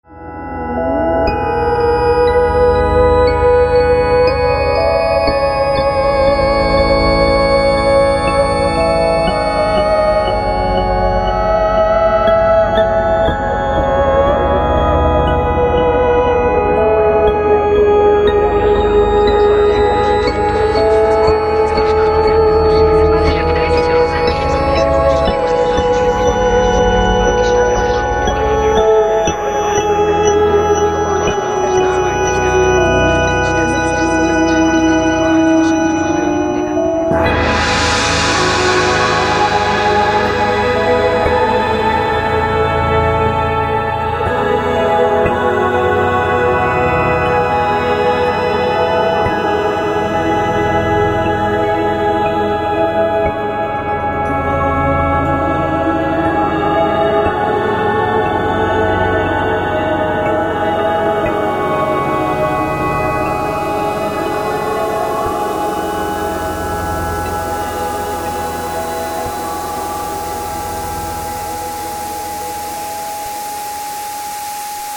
Theremin-Solo